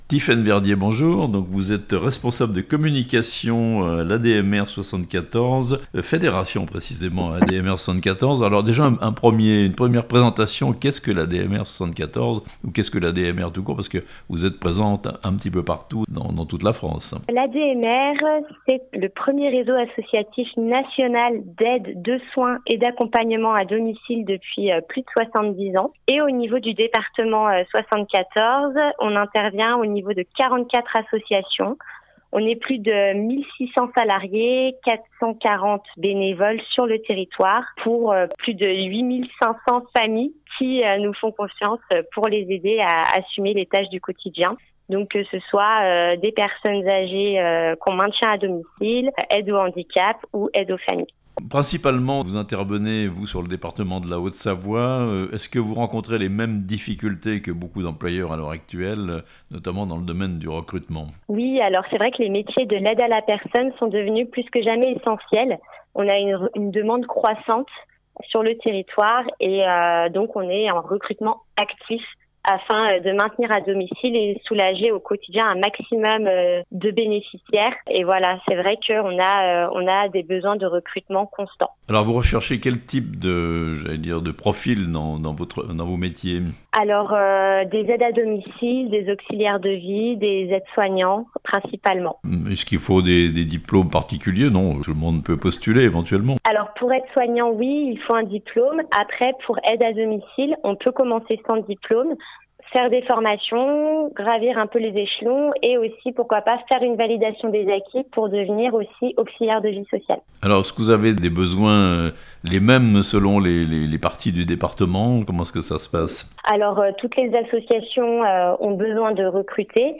L'ADMR de Haute-Savoie lance sa semaine de l'emploi (interview)